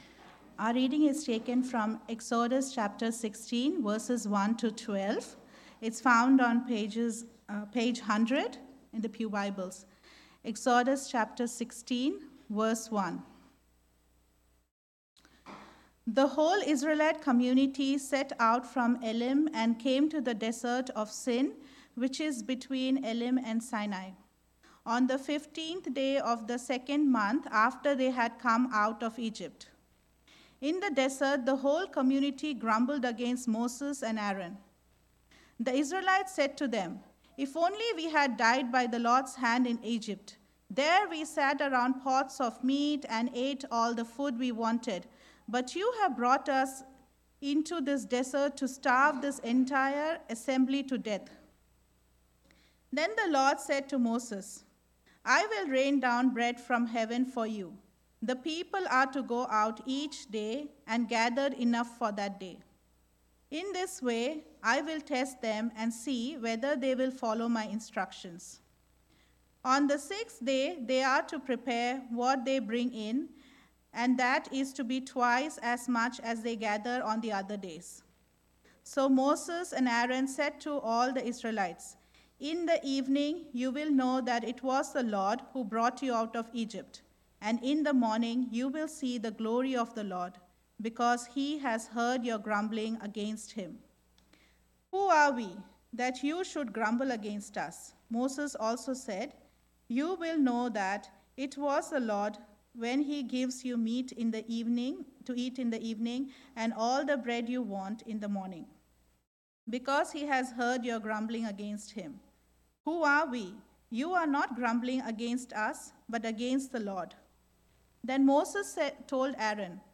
Sermons – Reservoir Presbyterian Church